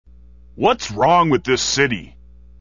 If you thought Resident Evil was the apex of bad voice acting, think again.